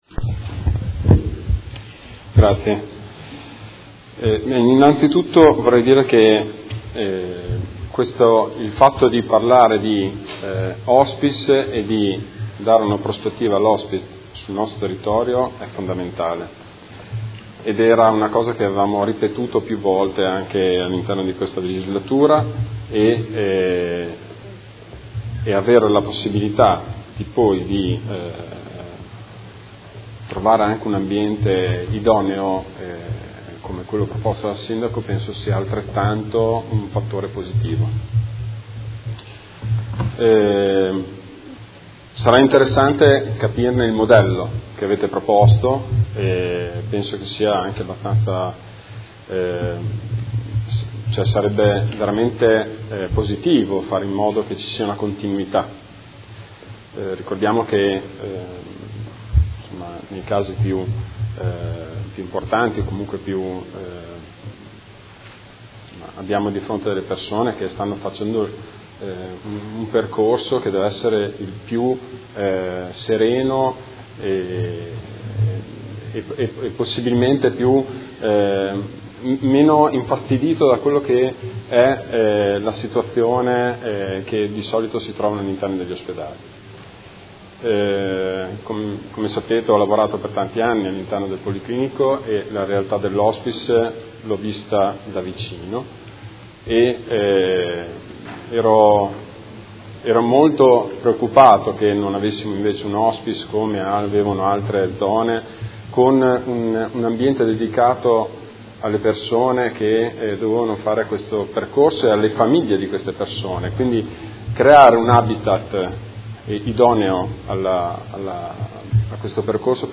Dibattito su Ordine del Giorno presentato dai Consiglieri Arletti, Fasano, Baracchi, Bortolamasi, De Lillo, Pacchioni, Liotti, Di Padova, Venturelli, Morini e Lenzini (PD) avente per oggetto: Anche Modena si merita un hospice “territoriale” - la programmazione sanitaria preveda una struttura per il territorio modenese analogamente alle altre province della Regione Emilia Romagna ed emendamenti